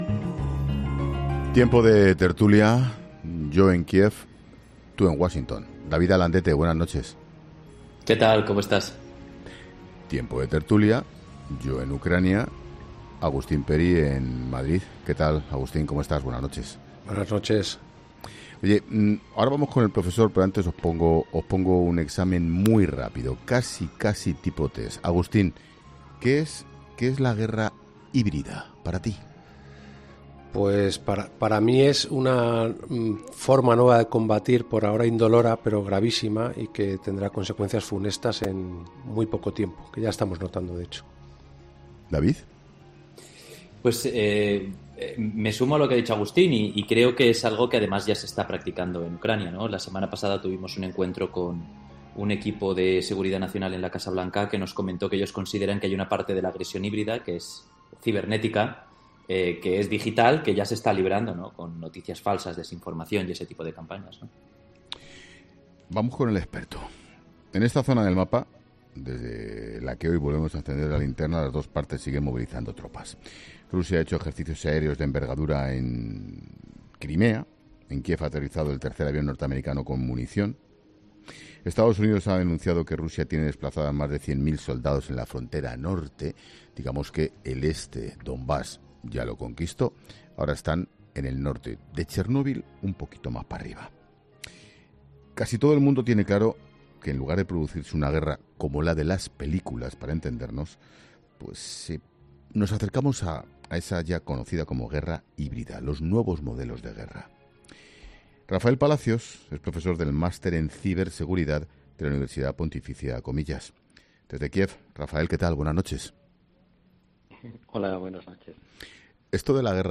Un experto en ciberseguridad explica en 'La Linterna' las claves de esta nueva forma de guerra con el uso de las nuevas tecnologías y las "fake news"